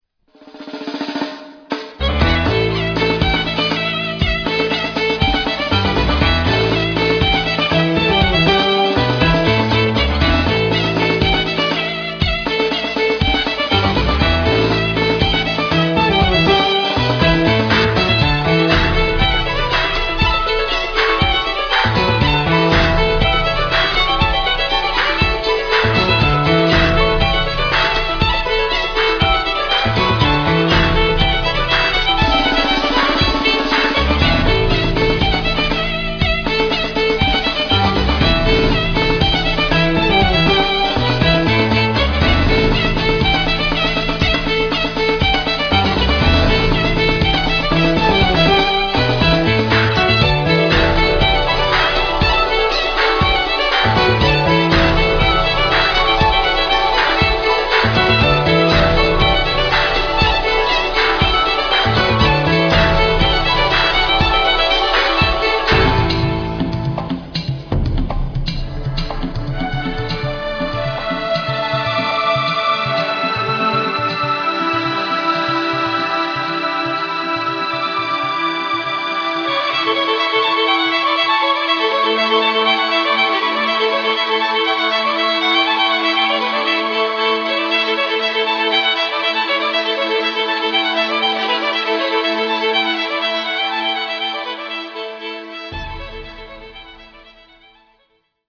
"strong, fiery ... compelling" - Dirty Linen